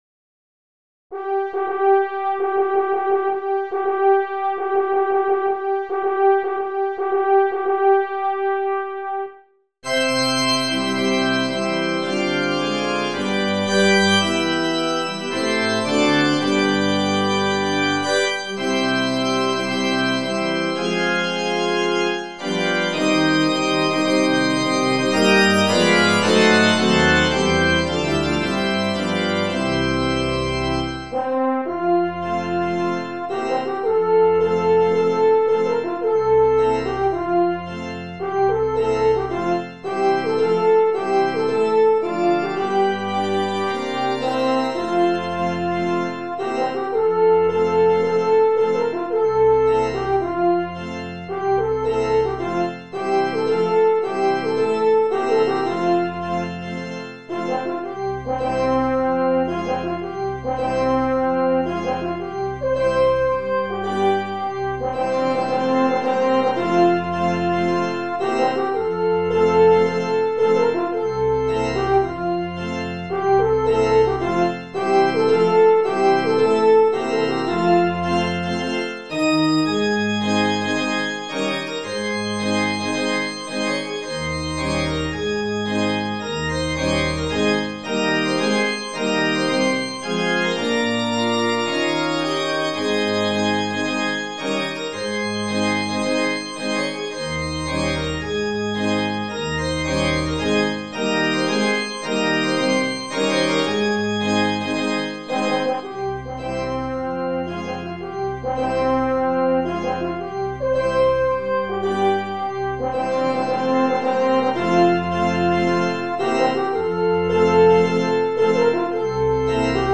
Voicing: Horn Solo